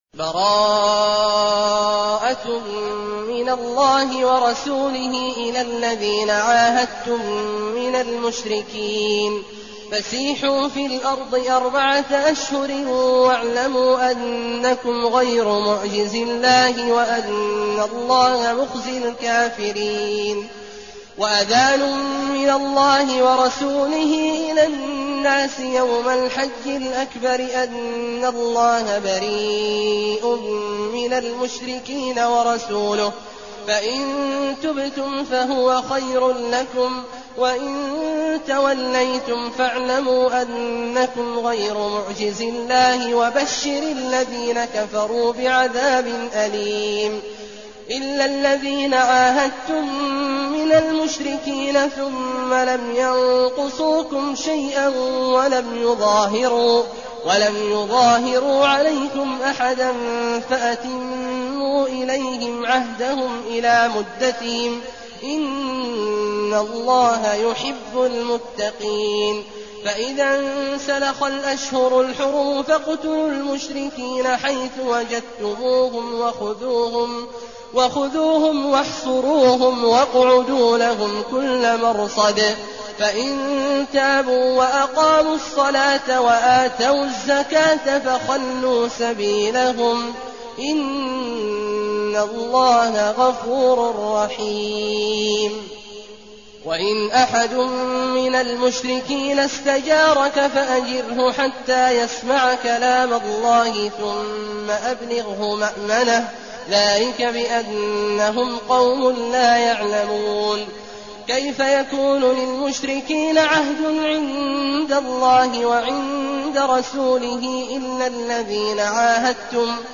المكان: المسجد النبوي الشيخ: فضيلة الشيخ عبدالله الجهني فضيلة الشيخ عبدالله الجهني التوبة